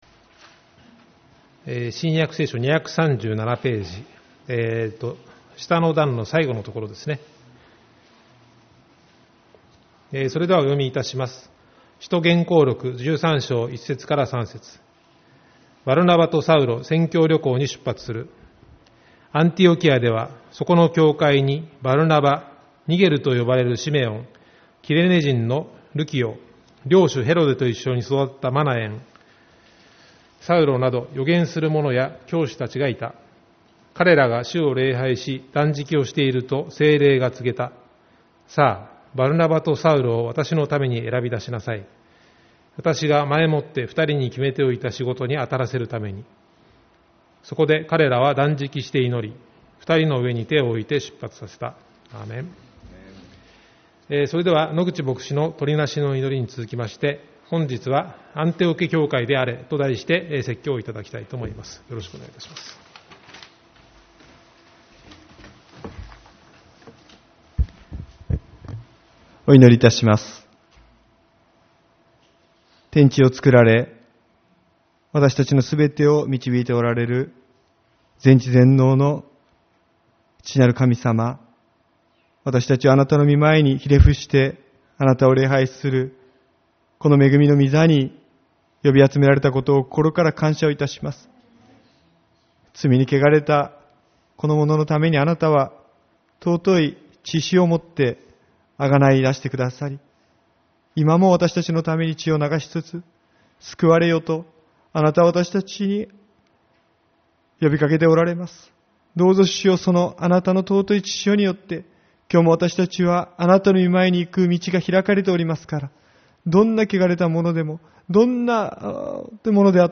9月22日主日礼拝